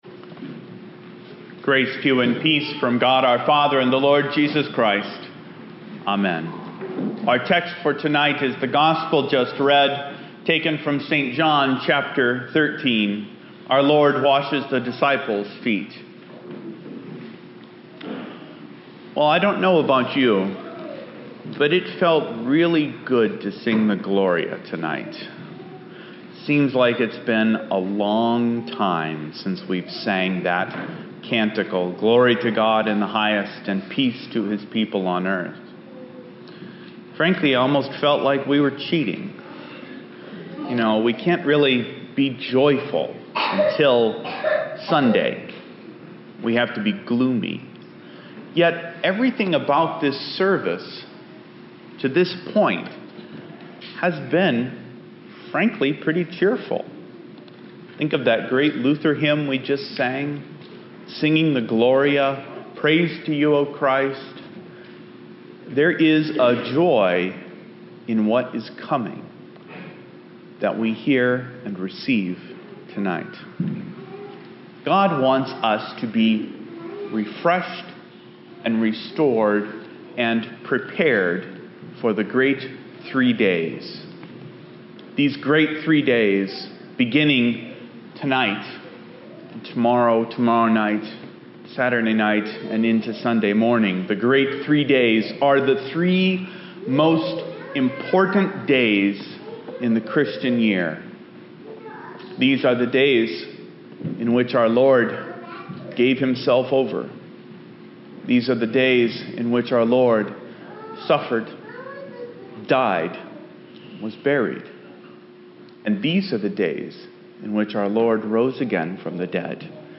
Â But here is the audio: Holy Thursday Sermon – 2011 “ Like this: Like Loading...